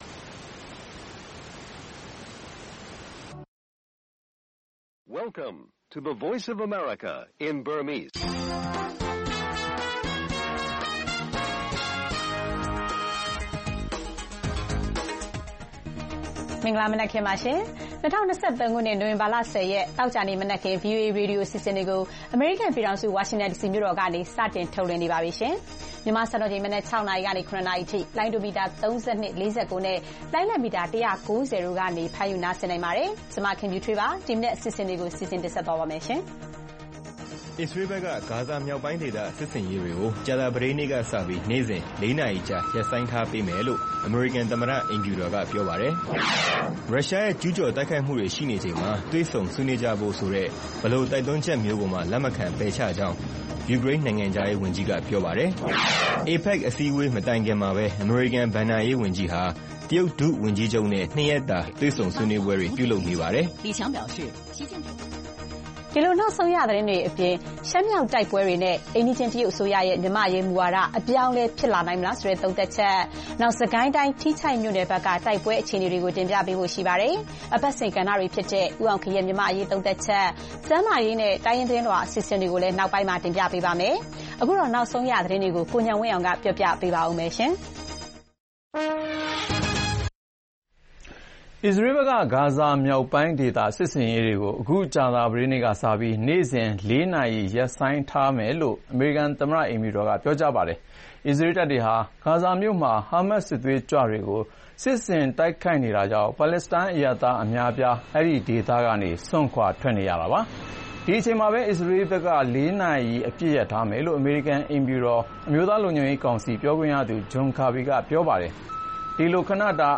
ဗွီအိုအေမြန်မာနံနက်ခင်း(နိုဝင်ဘာ ၁၀၊၂၀၂၃) အစ္စရေးက ဂါဇာစစ်ဆင်ရေးမှာ တနေ့ လေးနာရီအပစ်ရပ်ပေးမည်၊ မြန်မာရှမ်းမြောက်တိုက်ပွဲကို တရုတ်နိုင်ငံဘယ်လိုရှုမြင်သလဲ စတဲ့သတင်းတွေနဲ့ အပတ်စဉ်ကဏ္ဍတွေမှာ သတင်းသုံးသပ်ချက်၊ကျန်းမာရေး၊တိုင်းရင်းသတင်းလွှာတွေ ထုတ်လွှင့်ပေးပါမယ်။